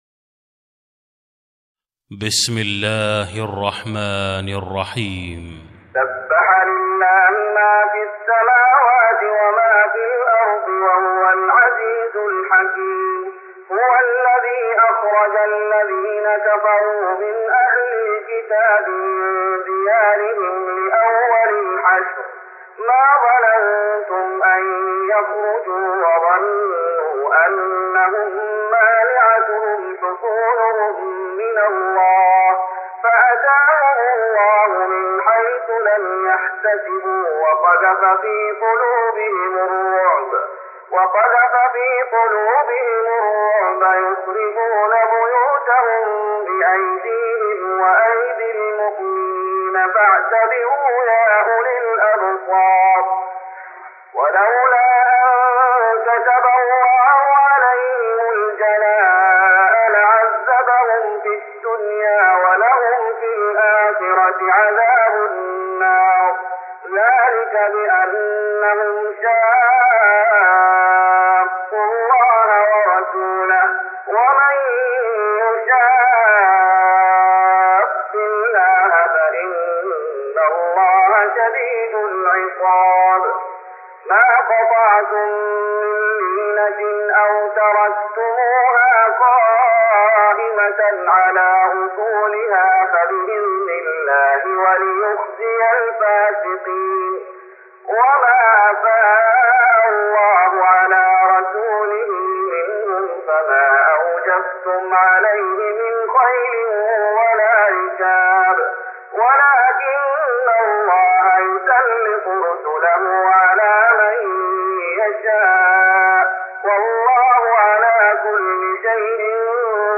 تراويح رمضان 1414هـ من سورة الحشر Taraweeh Ramadan 1414H from Surah Al-Hashr > تراويح الشيخ محمد أيوب بالنبوي 1414 🕌 > التراويح - تلاوات الحرمين